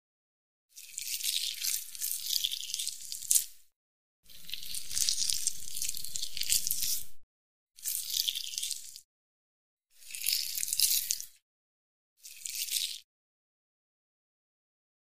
Incisions; 5 Incisions Into Flesh; Various Lengths, Close Perspective. Knife.